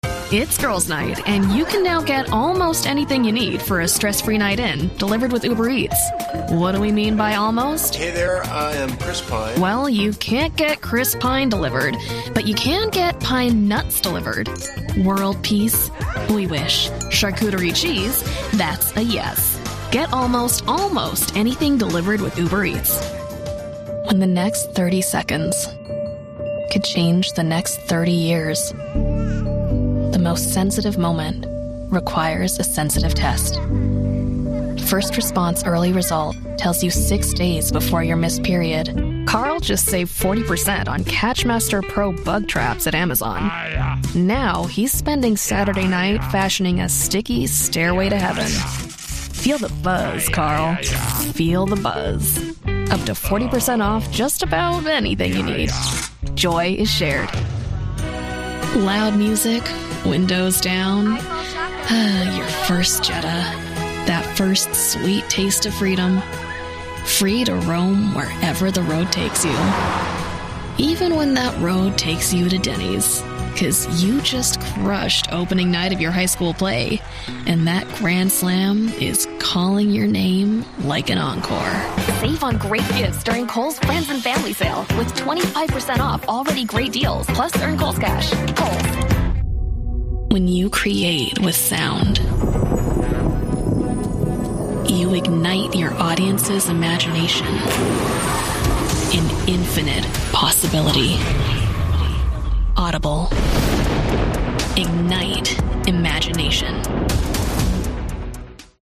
Modern
Wry
Friendly